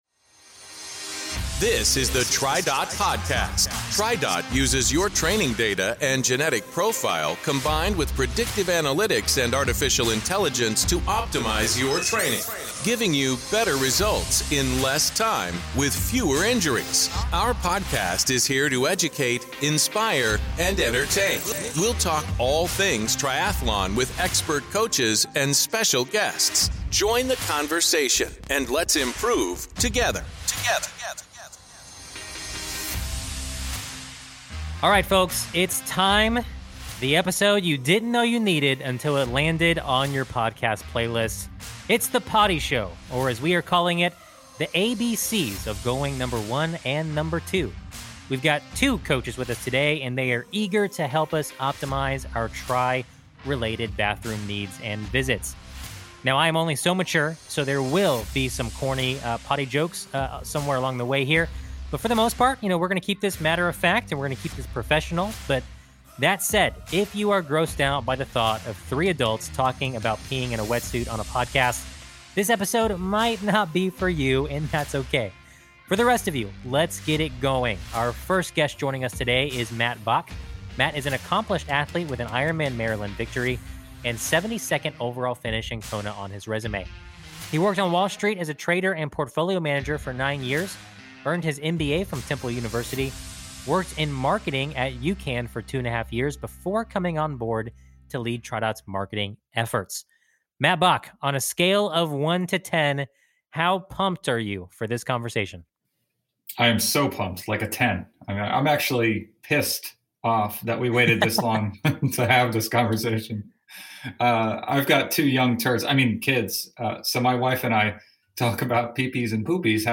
Join us for a mostly mature conversation about bathroom basics for triathletes.